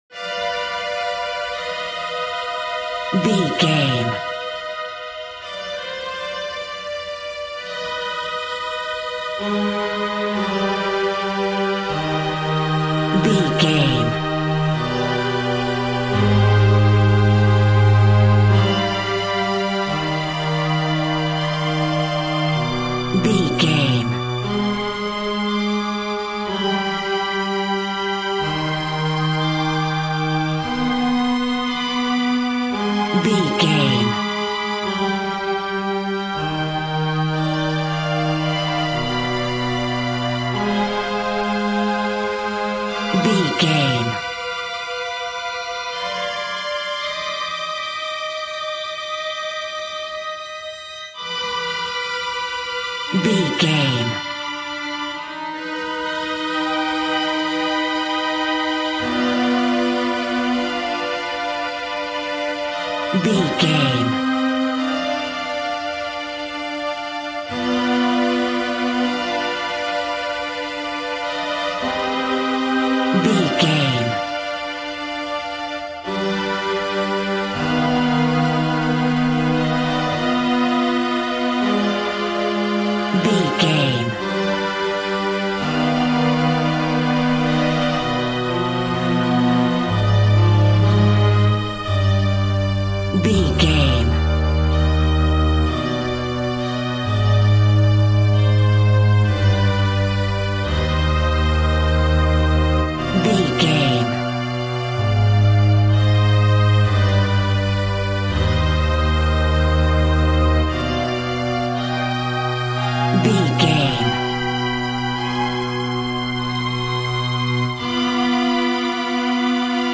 Scary String Quartet.
Aeolian/Minor
tension
ominous
dark
eerie
strings
violin
cello
double bass
viola